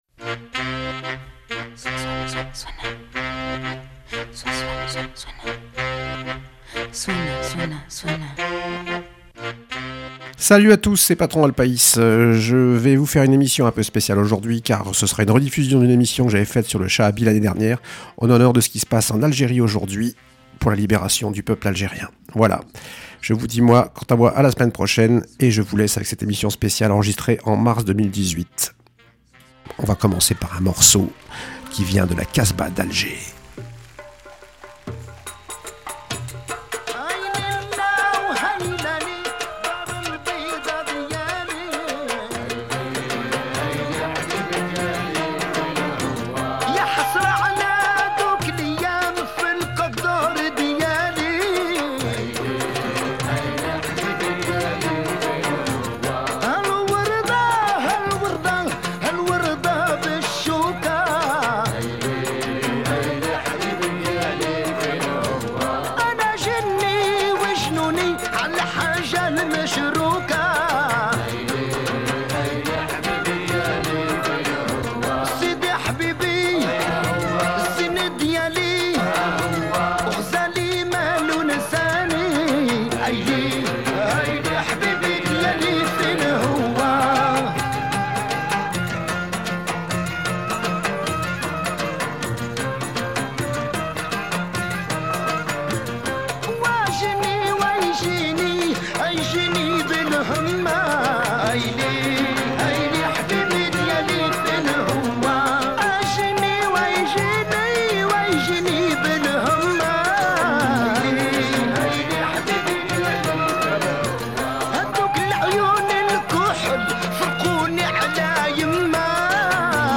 La rediffusion d’une émission de l’an passé consacrée au Chaabi algérien en l’honneur de ce qui se passe en Algérie en ce moment